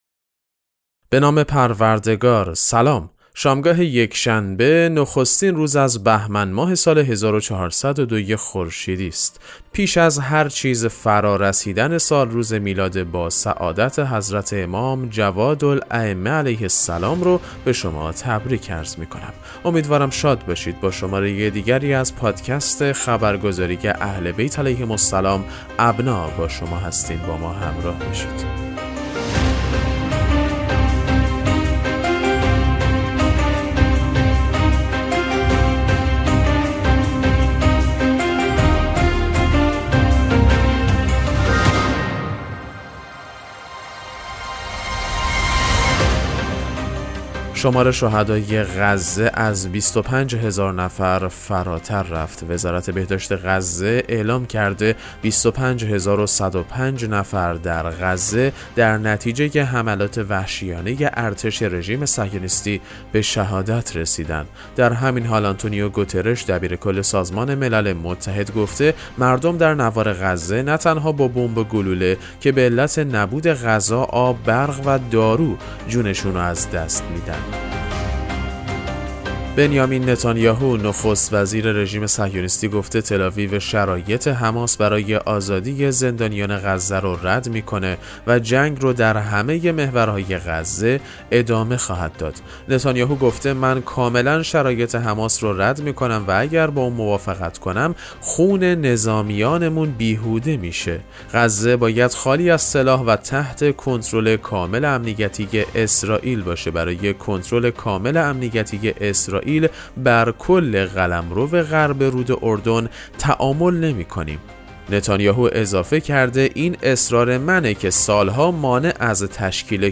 پادکست مهم‌ترین اخبار ابنا فارسی ــ یکم بهمن 1402